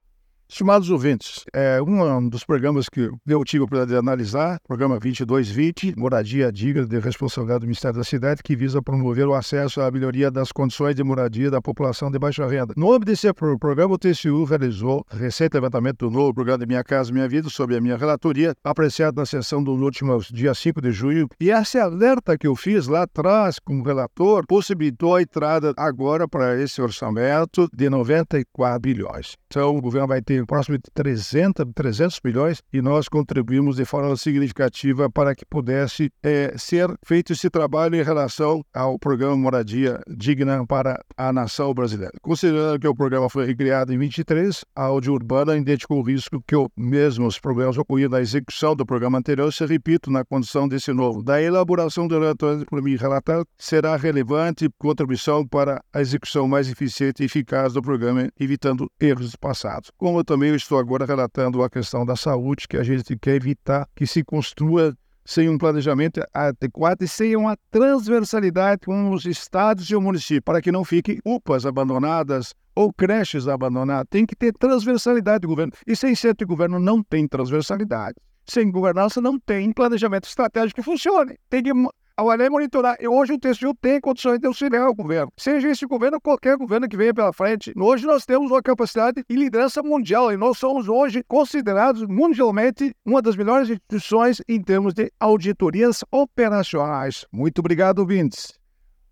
É o assunto do comentário do ministro do Tribunal de Contas da União, Augusto Nardes, desta segunda-feira (24/06/24), especialmente para OgazeteirO.